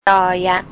ยอ-ยัก
yor yag